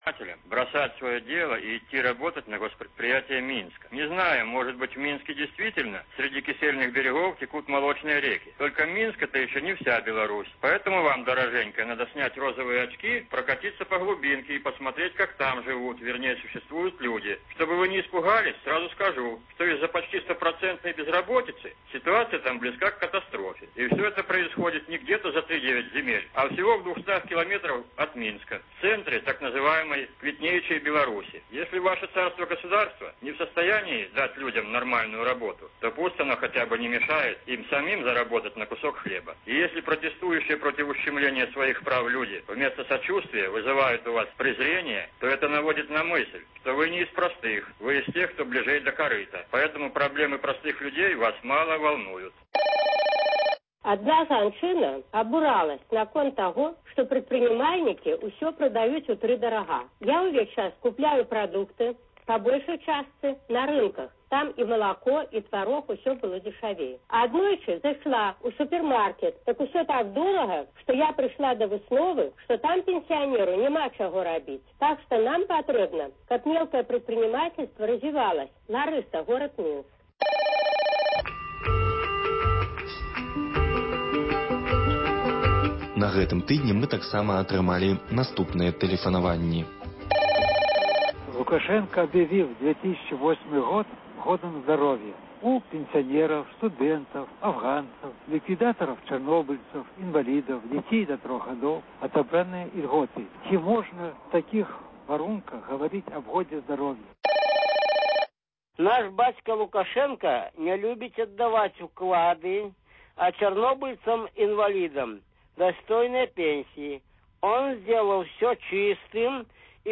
гутарыць з мастаком і літаратарам